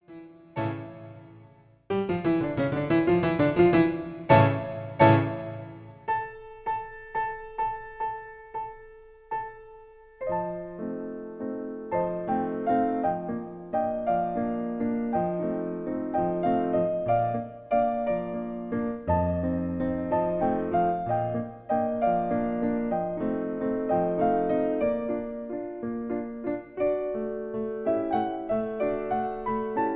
Ouverture zur gleichnamigen Operette